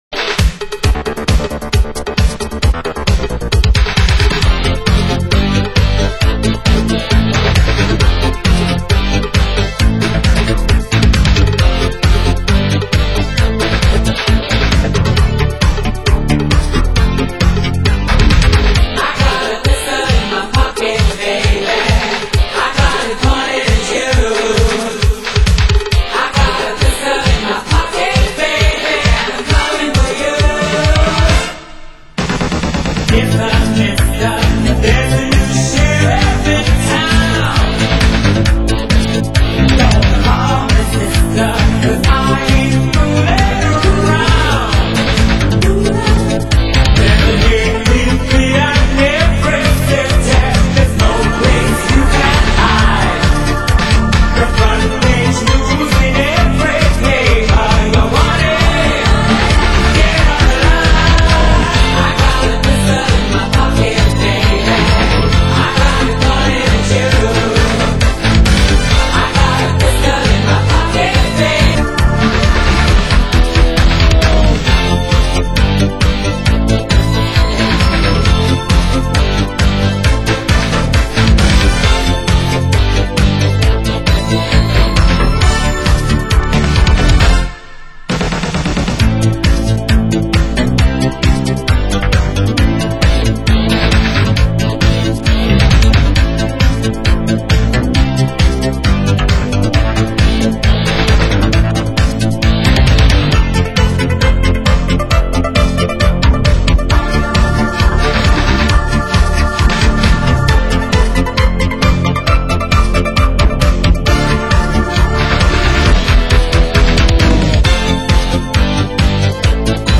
Genre: Synth Pop